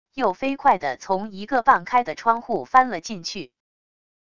又飞快地从一个半开的窗户翻了进去wav音频生成系统WAV Audio Player